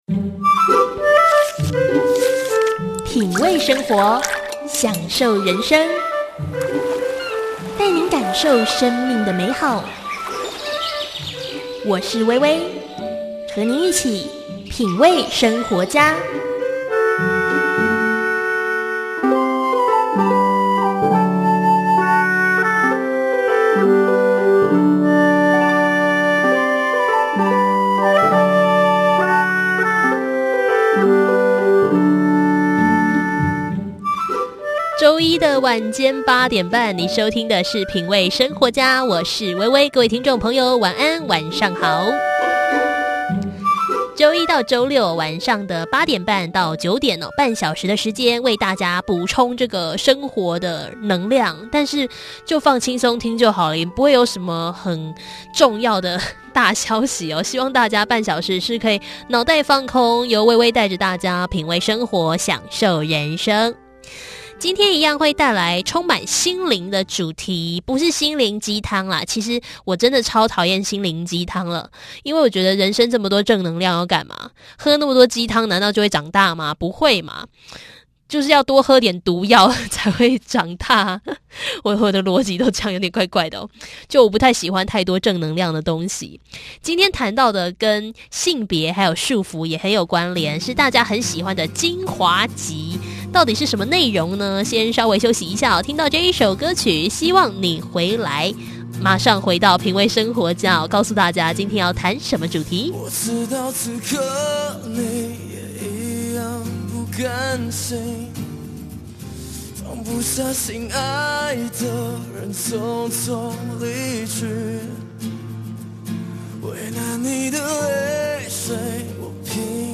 品味生活家精選《情緒勒索》作者、諮商心理師慕姿談談女性在生活中被他人框架的「應該」事項，就像是女性應該儀態優雅、下班回家後做飯打掃是天經地義、成為母親後就應該要照顧孩子等等，今天分享生活中那些曾被你忽略的束縛。